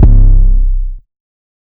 808 [ quarter ].wav